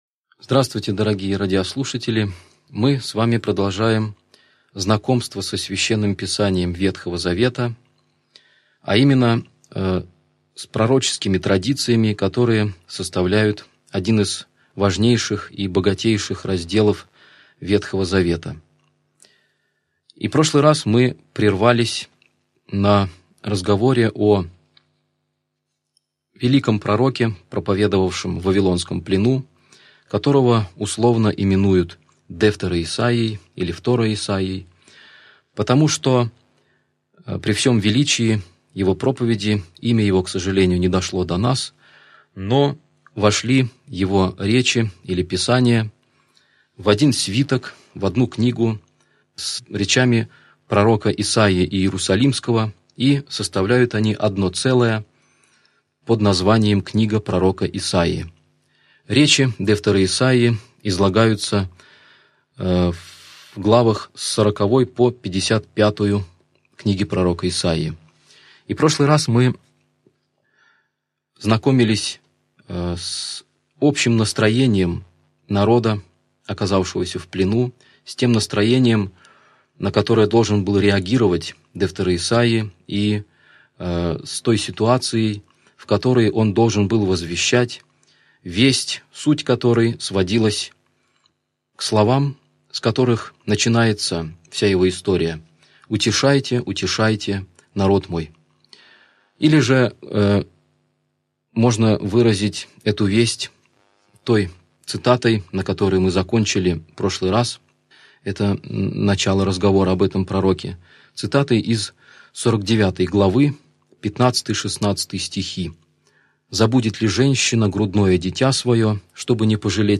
Аудиокнига Лекция 22. Пророк Второисаия (продолжение) | Библиотека аудиокниг